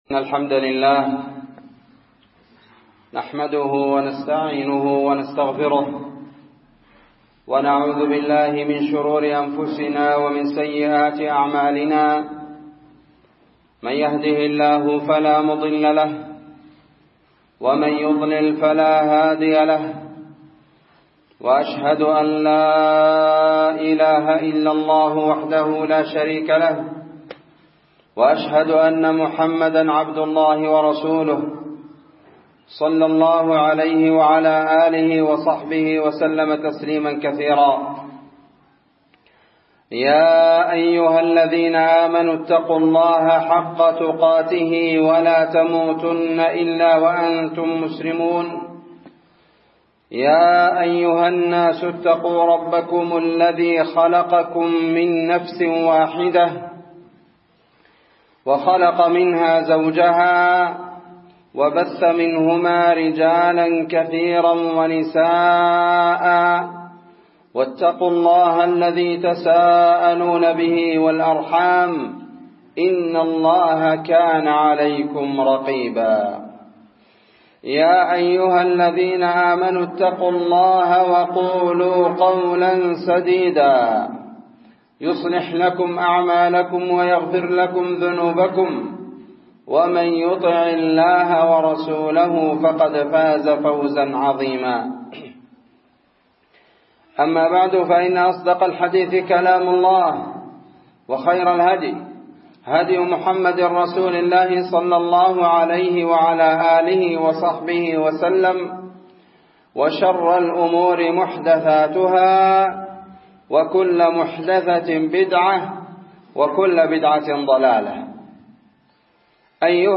خطبة بعنوان تفسير سورة الزلزلة 6 جمادى الآخرة 1444
في مسجد خالد بن الوليد في منطقة الفلوجة من أطراف هرجيسا